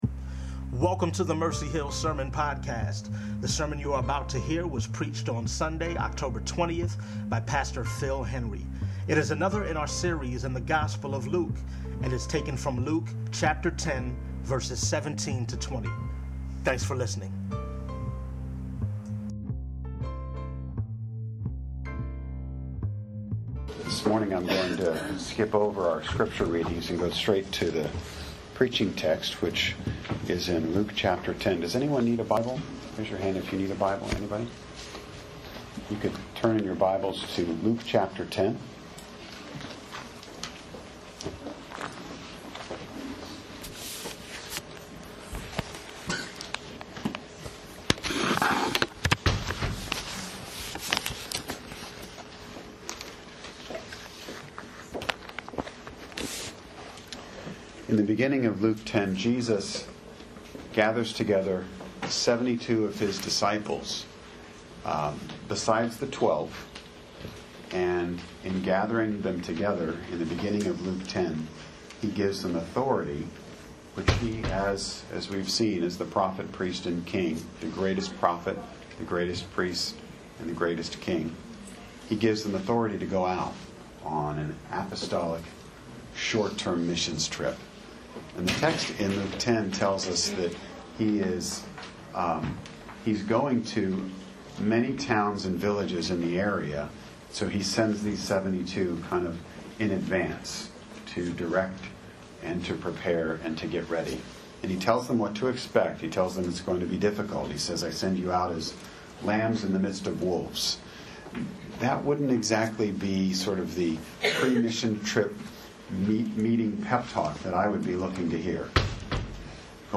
Mercy Hill Presbyterian Sermons - Mercy Hill NJ